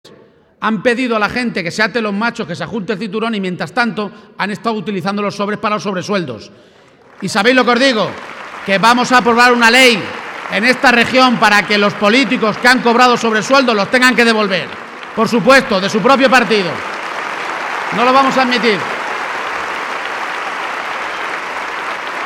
García-Page se pronunciaba de esta manera esta tarde, en Toledo, en un acto político que ha congregado a más de 1.500 personas en el Palacio de Congresos de la capital regional y en el que ha compartido escenario con el secretario general del PSOE, Pedro Sánchez, y la candidata socialista a suceder al propio García-Page en la alcaldía de Toledo, Mlagros Tolón.